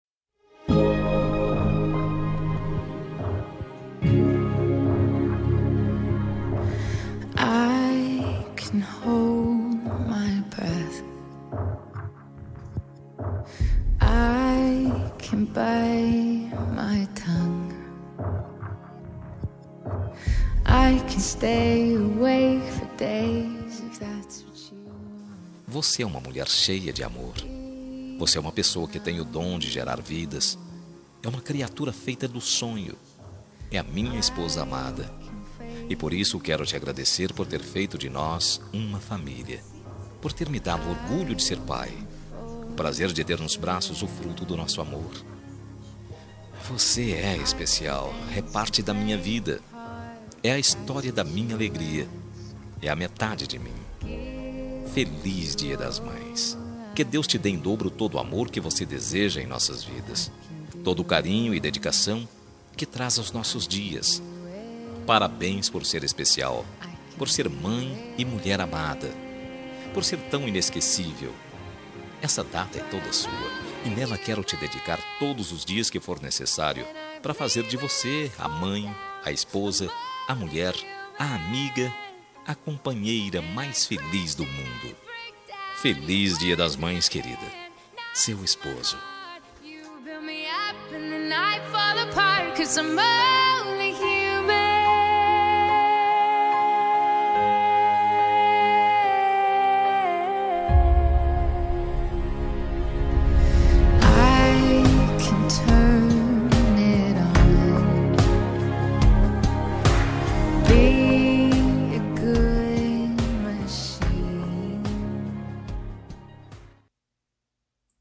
Dia das Mães – Para mãe de Consideração – Voz Masculina – Cód: 6530